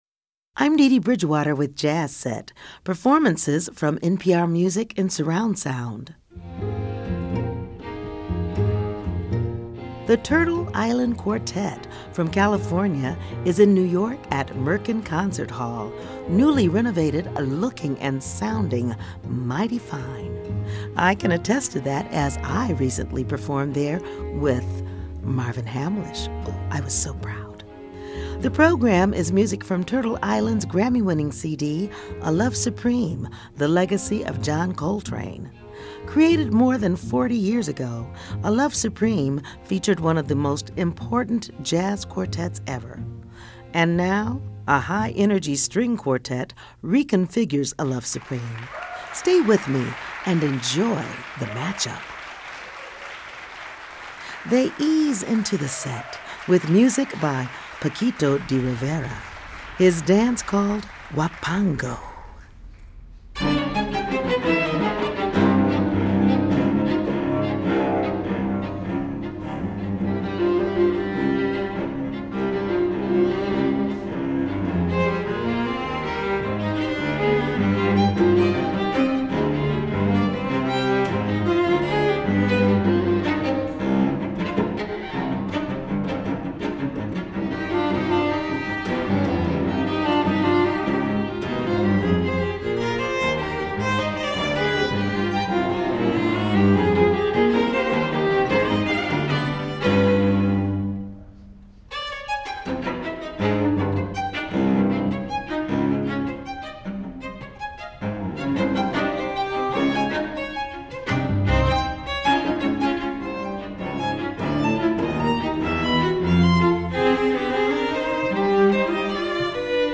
remains a towering and seemingly untouchable jazz classic.